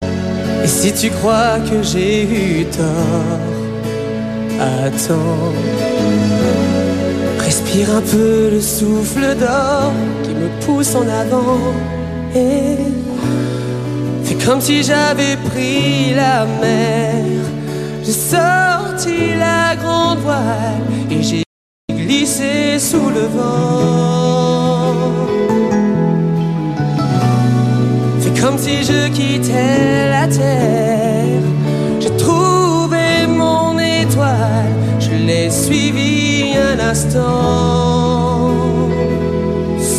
поп
романтические
live